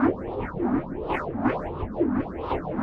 Index of /musicradar/rhythmic-inspiration-samples/85bpm
RI_RhythNoise_85-03.wav